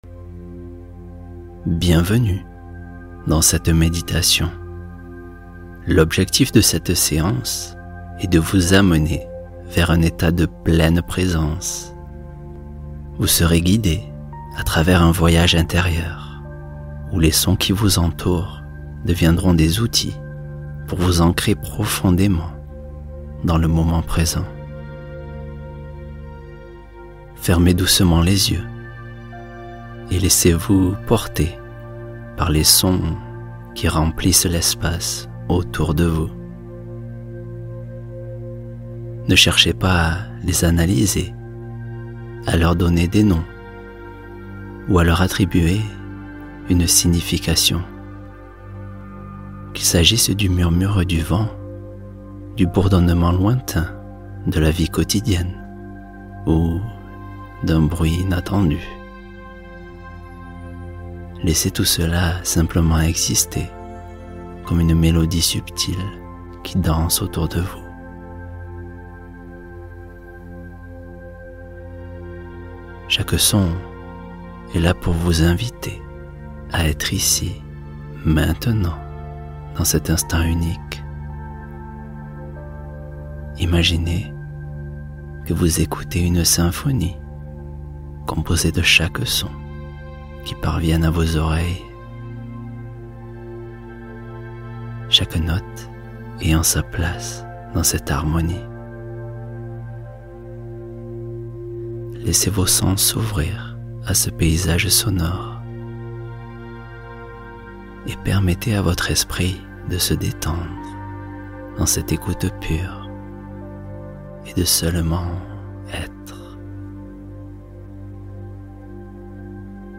Apaiser l’anxiété — Méditation guidée de lâcher-prise